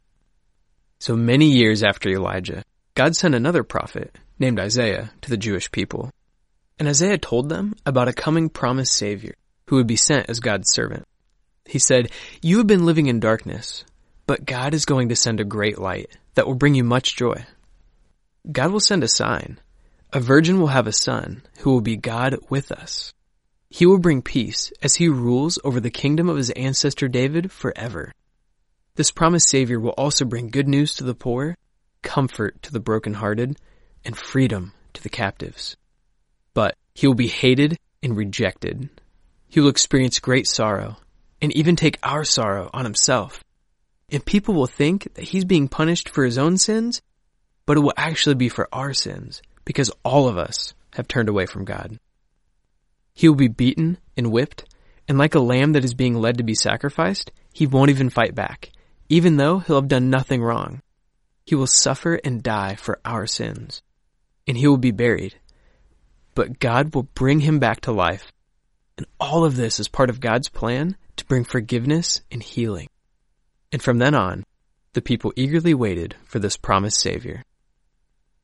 This Advent season, anticipate the celebration of Christmas with oral Bible stories.